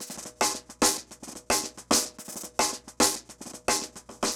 RemixedDrums_110BPM_50.wav